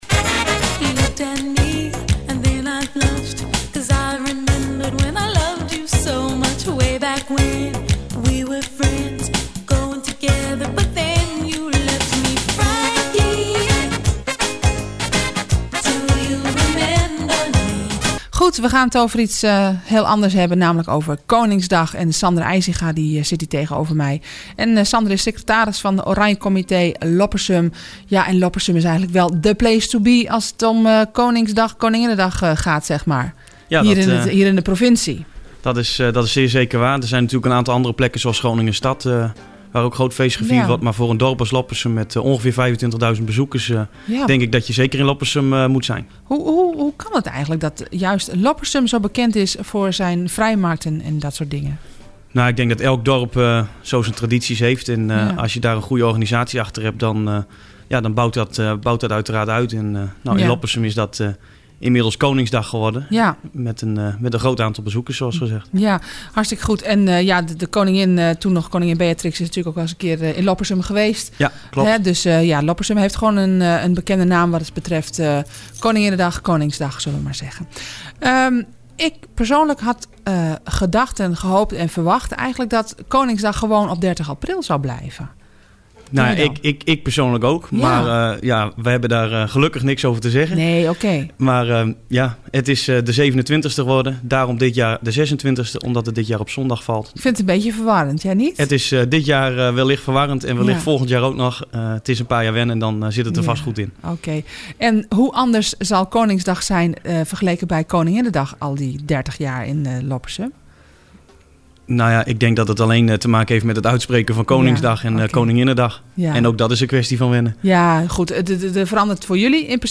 Na aanleiding van het uitgebracht persbericht heeft Havenstad FM ons uitgenodigd voor een interview over Koningsdag in Loppersum.